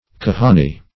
Kahani \Ka"ha"ni\, n. A kind of notary public, or attorney, in the Levant.